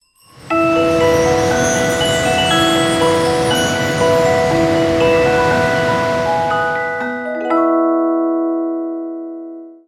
Ripped from the game